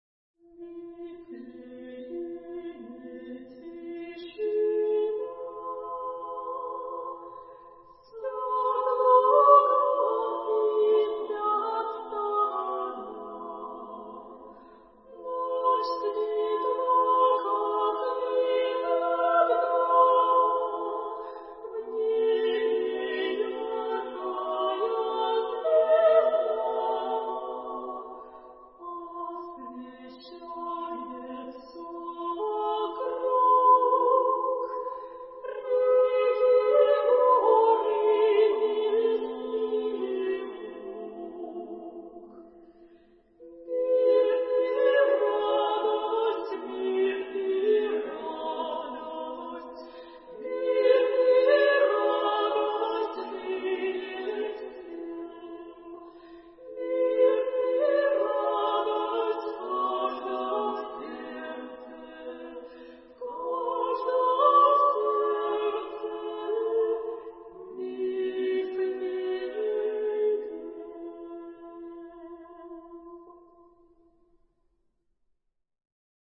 klirosnyj_khor_cerkvi_rozhdestva_i.predtechi_chesm.mp3